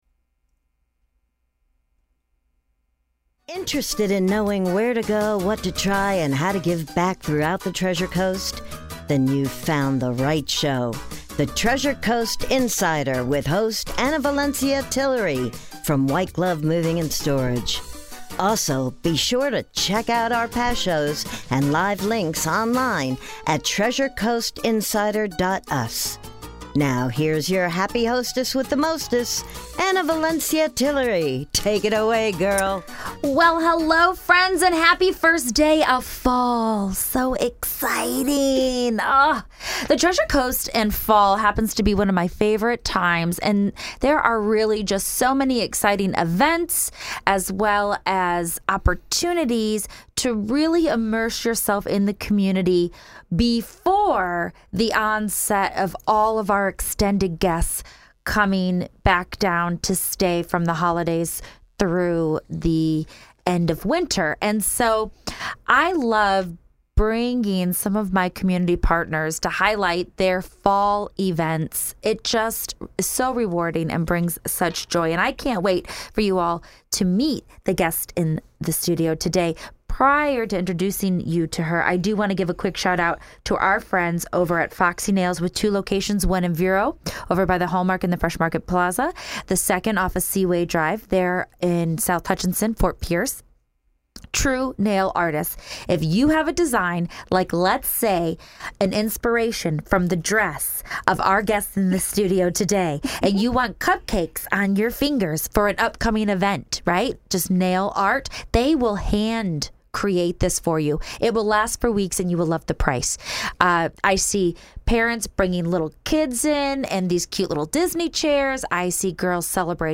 With great excitement, the two ladies chat about the changes to the annual Battle of the Realtors bowling event and the new storefront inside the Vero mall. Best part, Home Depot is helping and there are big changes ahead that all the volunteers will love.